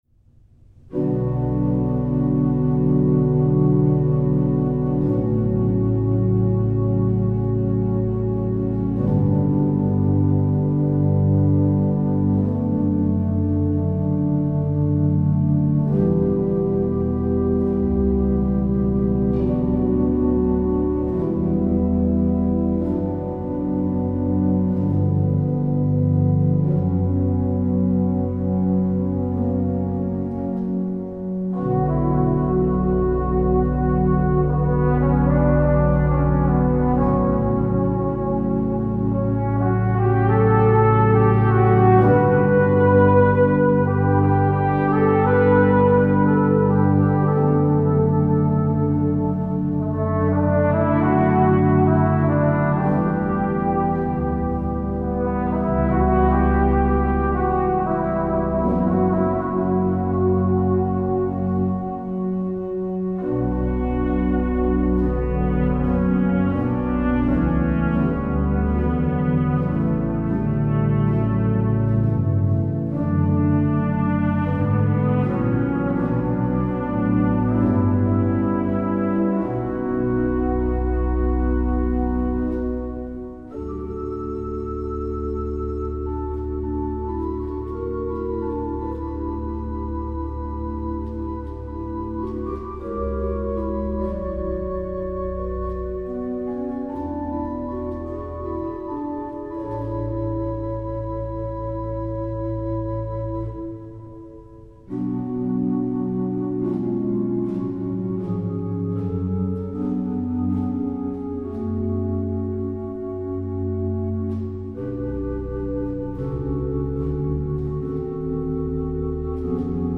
Trompetduo